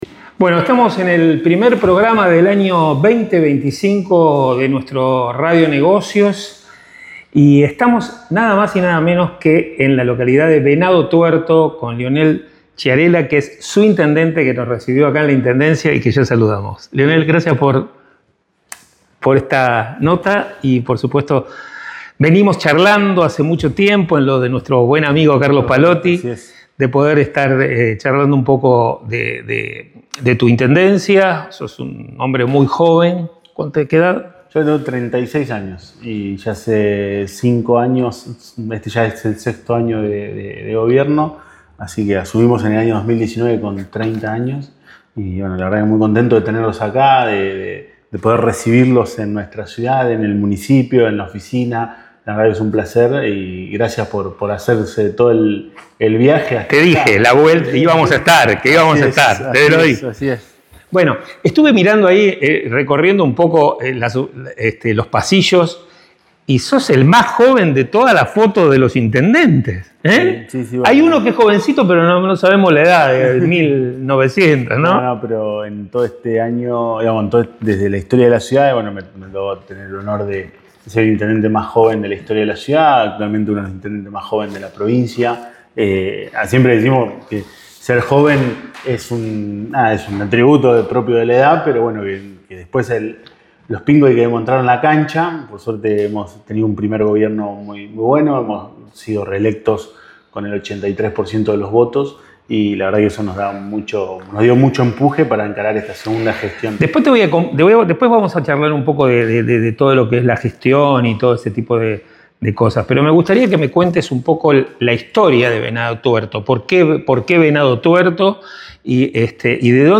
Entrevista a Leonel Chiarella – Intendente de Venado Tuerto.